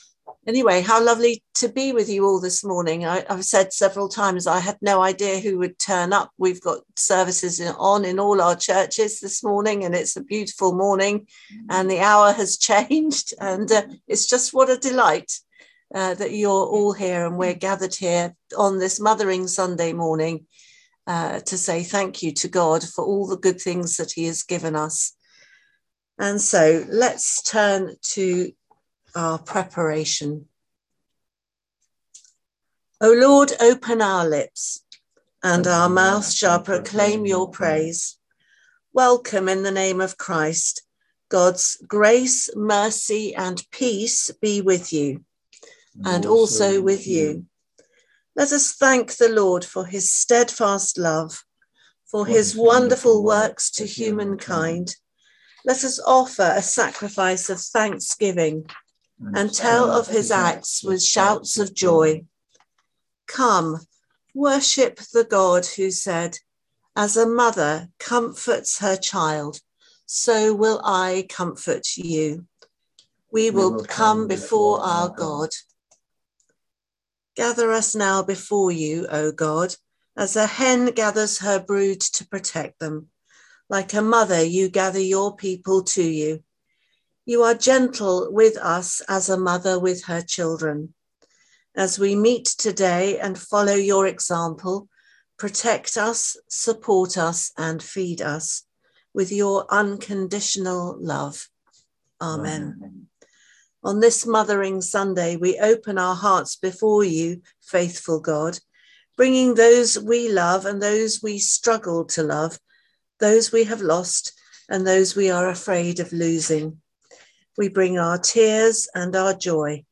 The audio from the Zoom / Conference Call service on Easter Sunday 17/04/2022.